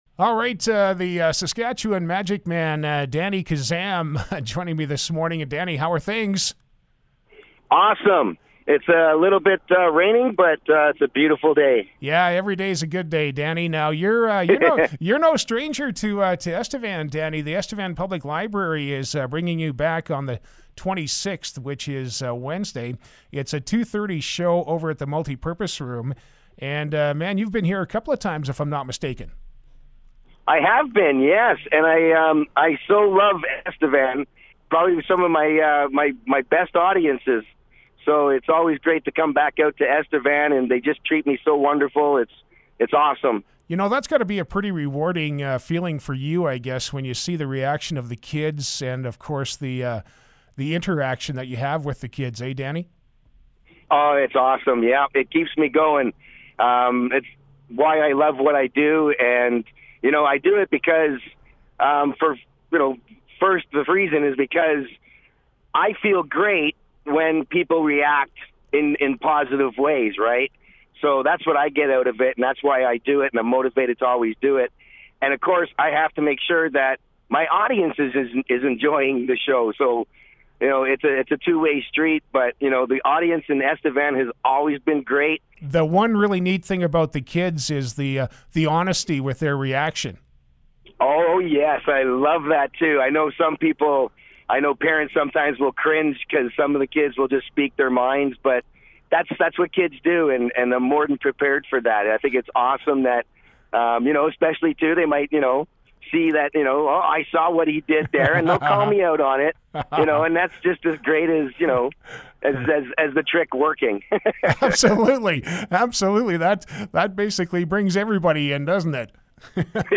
Listen to radio interview here Link to the original source